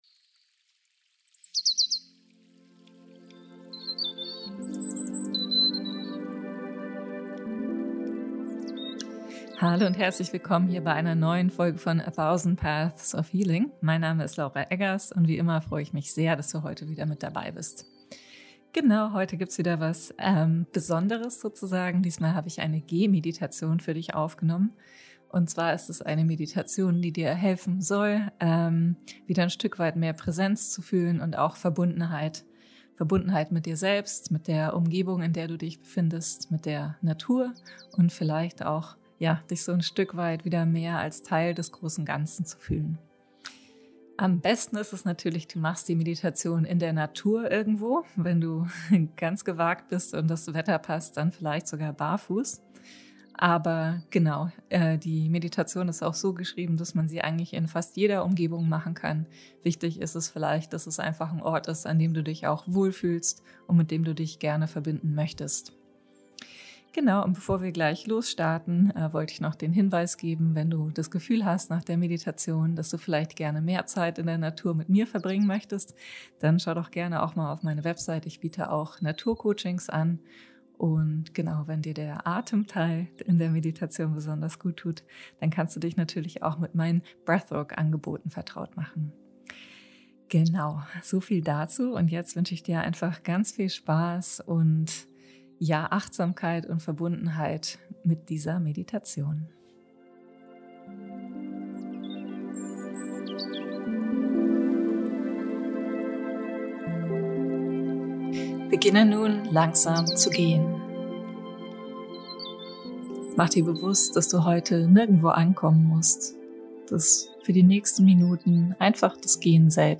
In dieser Folge von A Thousand Paths of Healing lade ich dich zu einer geführten Gehmeditation in der Natur ein. Diese Meditation unterstützt dich dabei, langsamer zu werden, im Körper anzukommen und dich wieder als Teil der lebendigen Welt um dich herum zu erleben.
gehmeditation.mp3